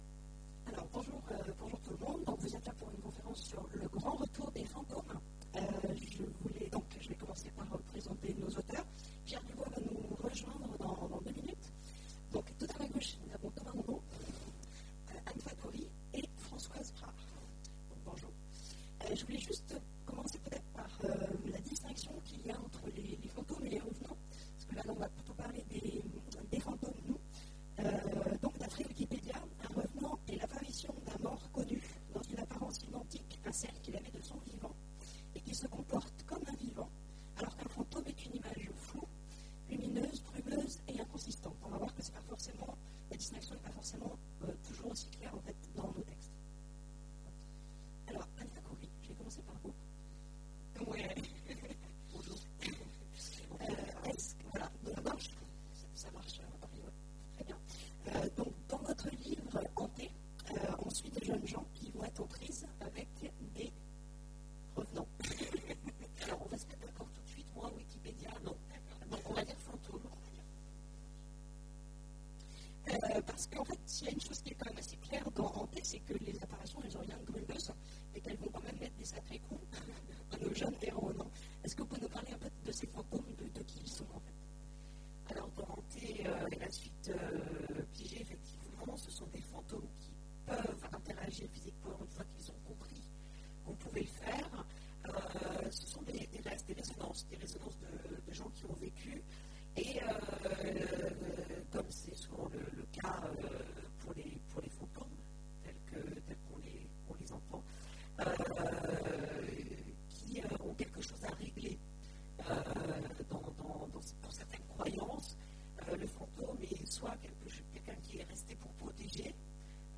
Imaginales 2015 : Conférence Le grand retour des fantômes